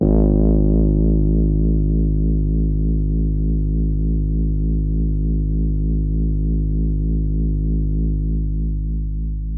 Buzzed Juno One Shot .wav